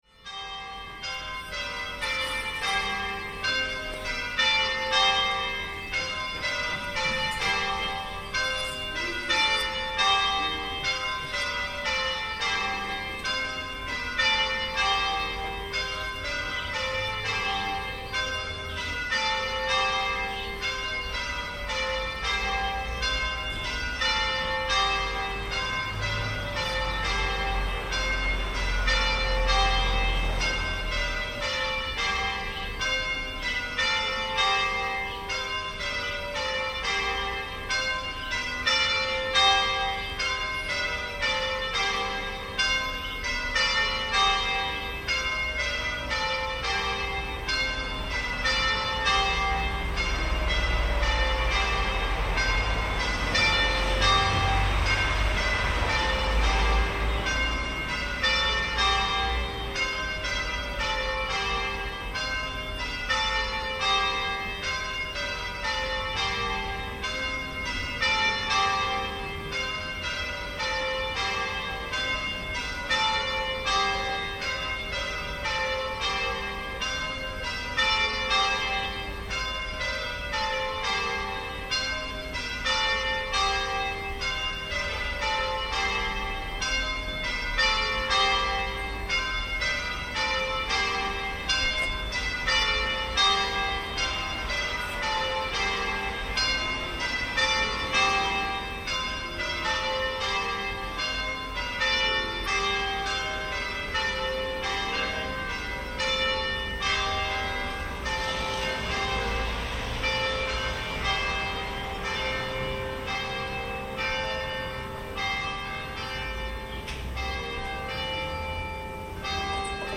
Bells call the village of Abbazia Pisani, Italy to mass on a Sunday morning in August.